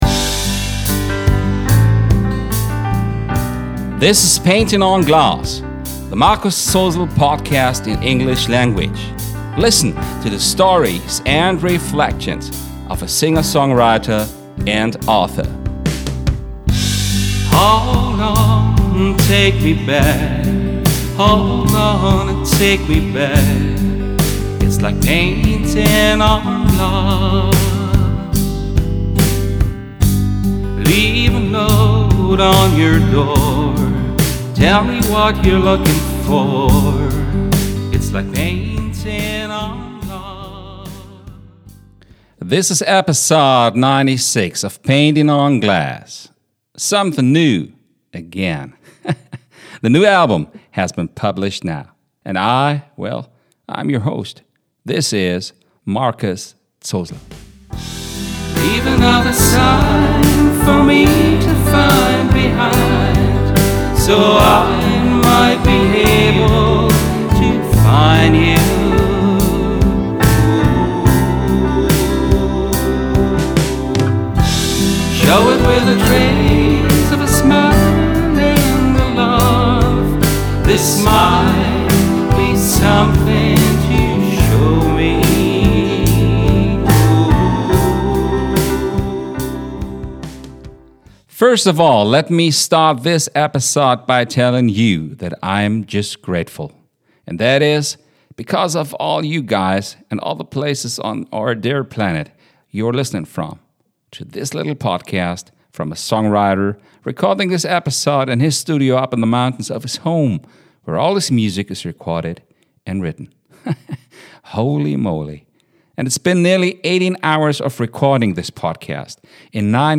In this episode he gives you an insight about the concept of it and he shares the story of creating it with the featured title song.